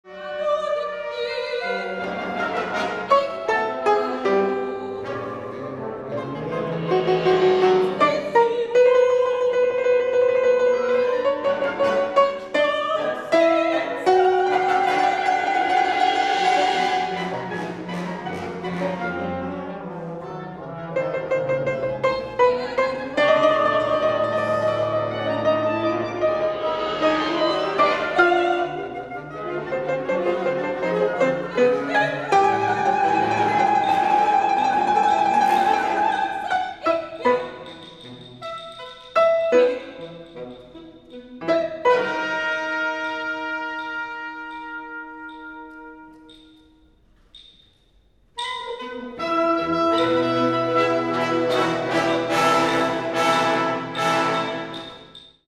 concerto dal vivo
audio 44kz stereo